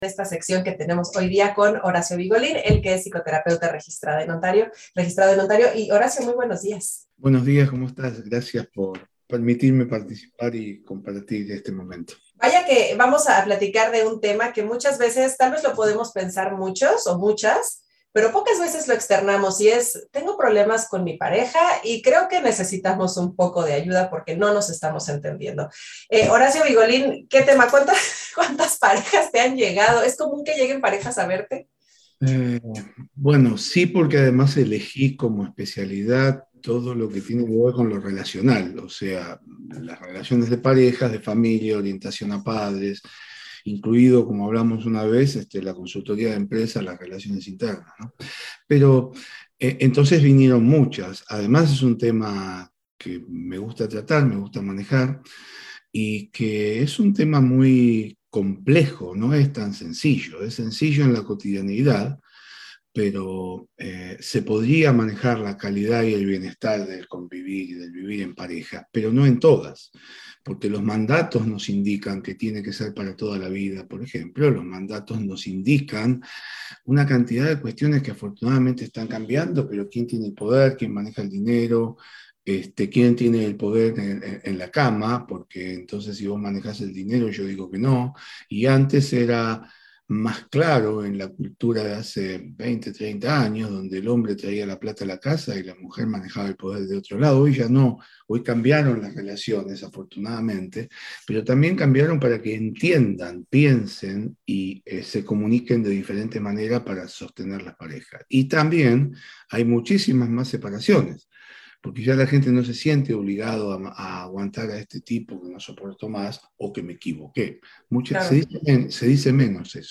en entrevista para CHHA 1610 AM